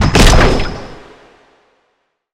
sci-fi_explosion_02.wav